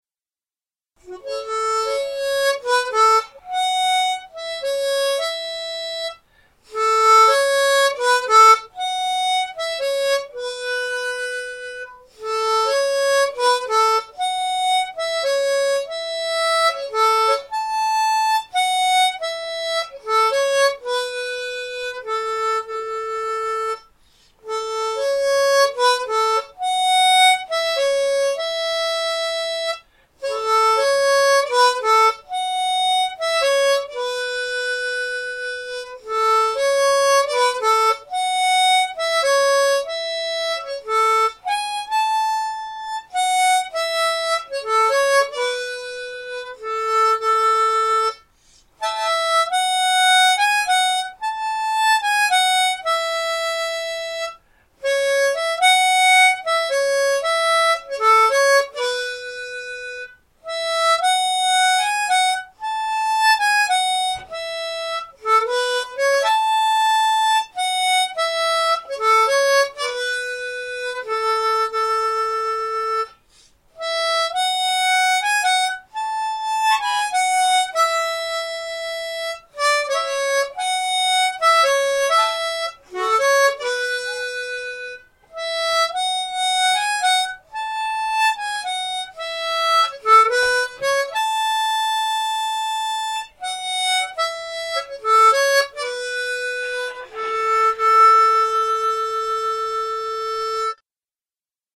Tunes on wooden flute.
The flute is a keyless blackwood flute of unknown make.